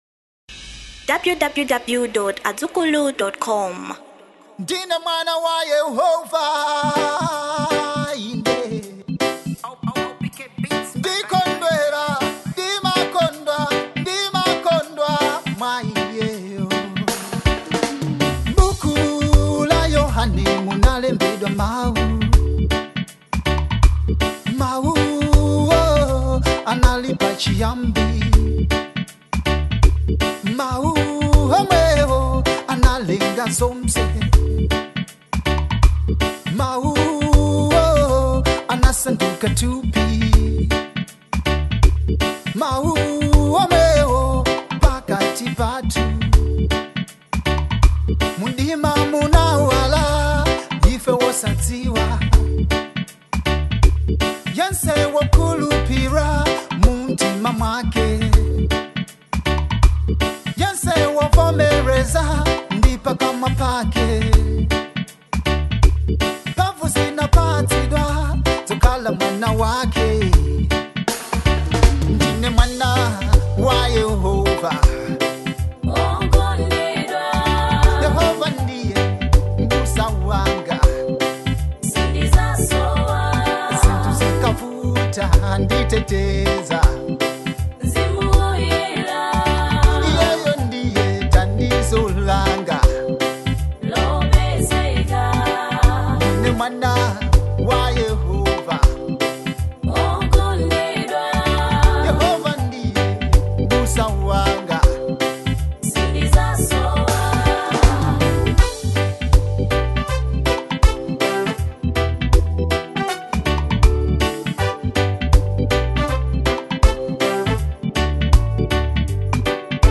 GOSPELS